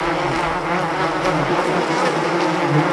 bzzbzz.wav